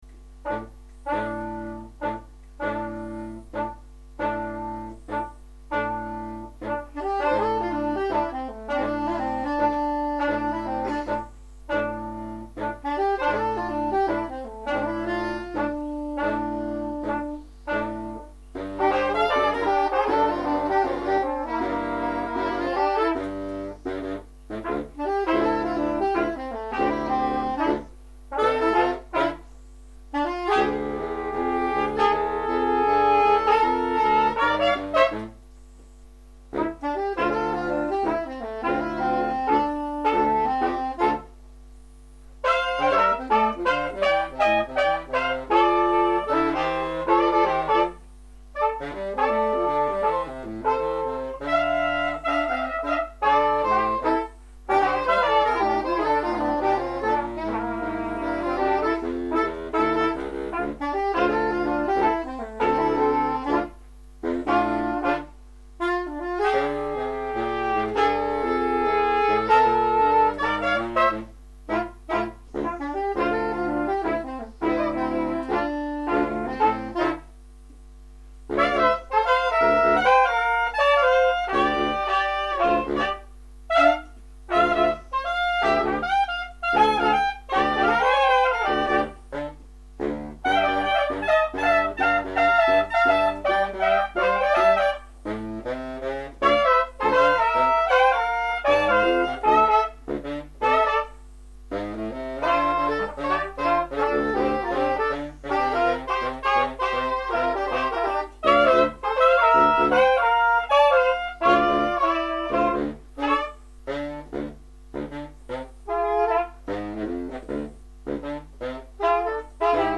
ss, bars, tp/flh, !perf
· Genre (Stil): Jazz
· Kanal-Modus: stereo · Kommentar